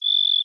Sparrow 002.wav